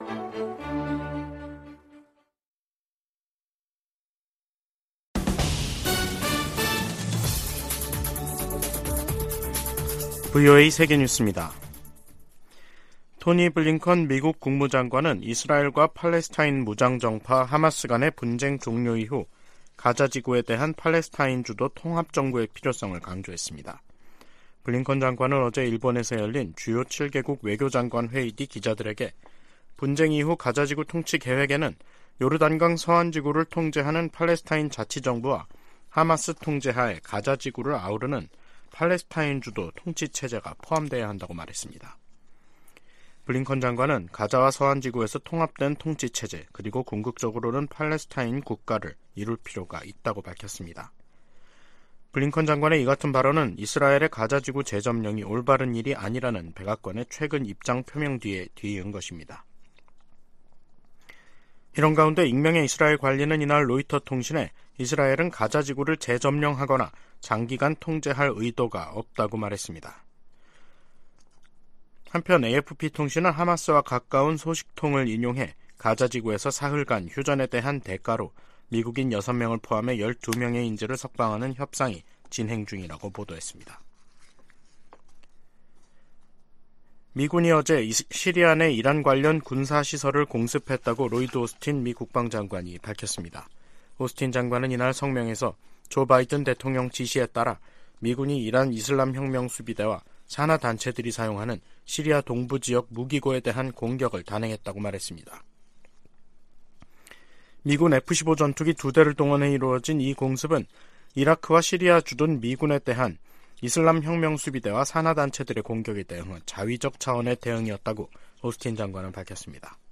VOA 한국어 간판 뉴스 프로그램 '뉴스 투데이', 2023년 11월 9일 2부 방송입니다. 토니 블링컨 미 국무장관과 박진 한국 외교장관이 9일 서울에서 만나 북러 군사협력 문제 등 현안을 논의했습니다.